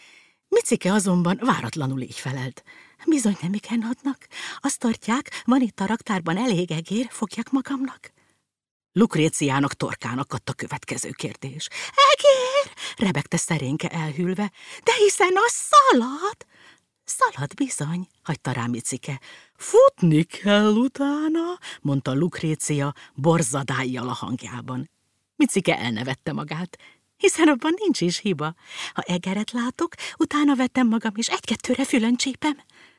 Album: Hangoskönyvek gyerekeknek